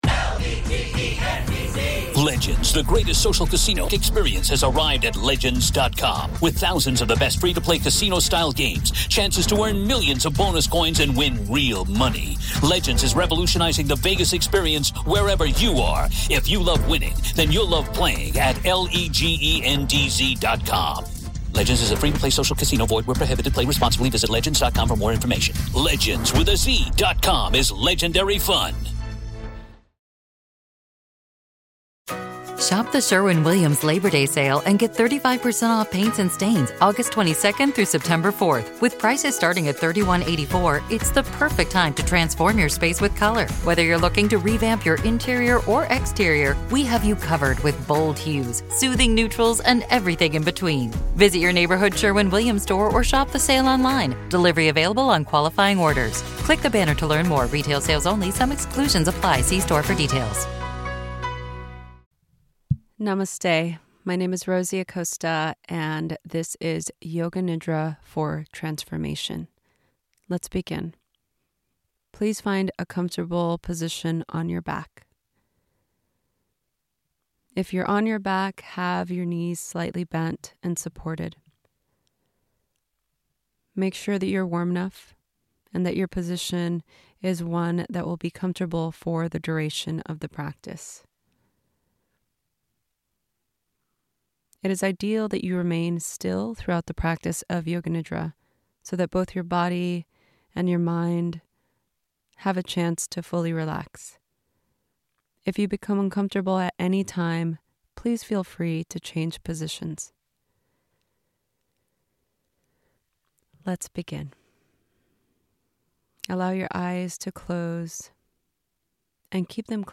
MEDITATION| Yoga Nidra For Transformation